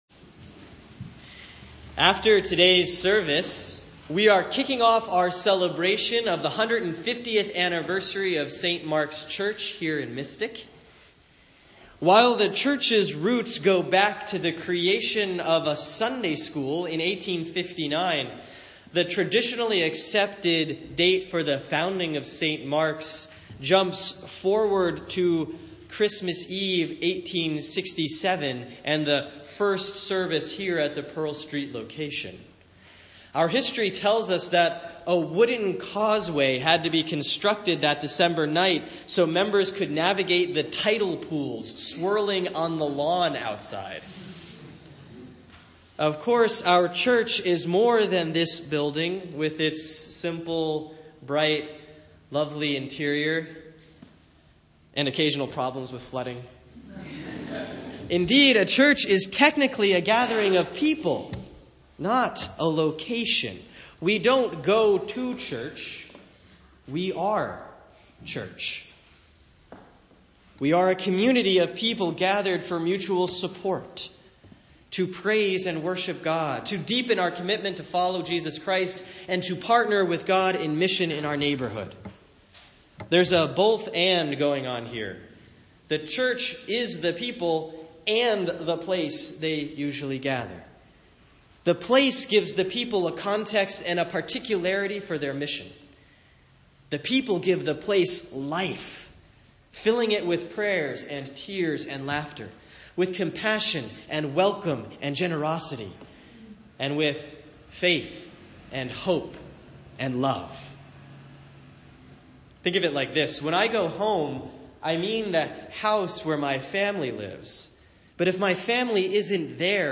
Sermon for Sunday, April 30, 2017 || The Feast of St. Mark (transferred) || Mark 1:1-15